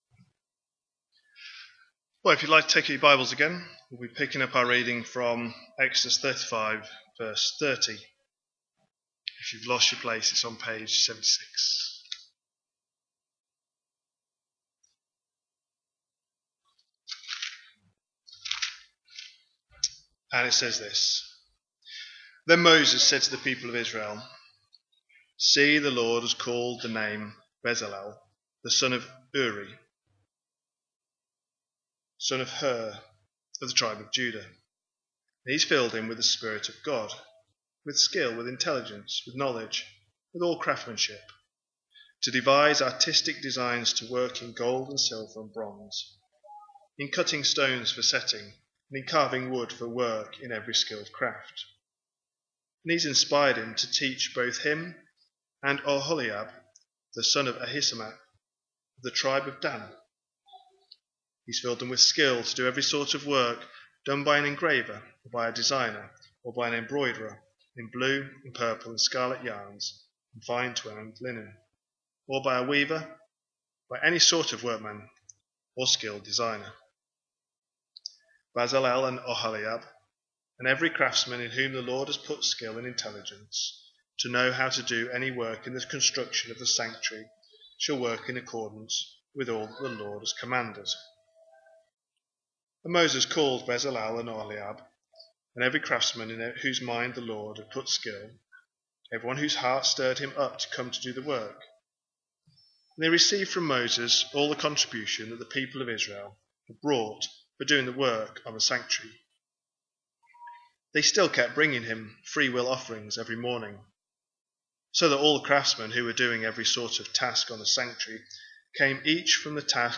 A sermon preached on 28th September, 2025, as part of our Exodus series.